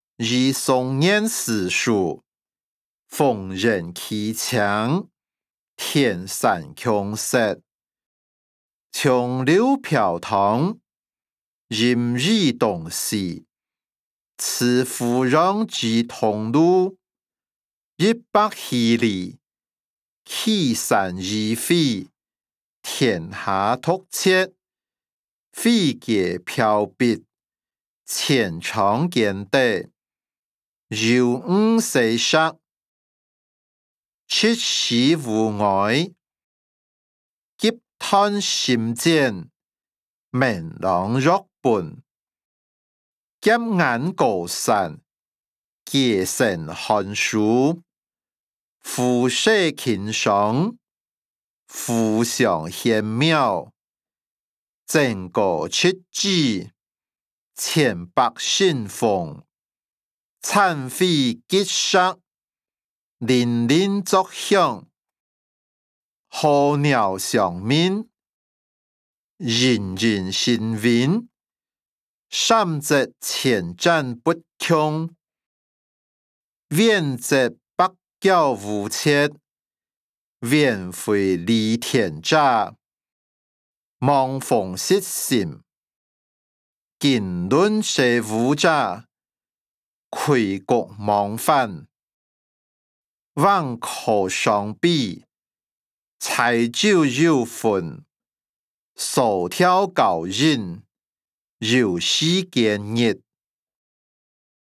歷代散文-與宋元思書音檔(饒平腔)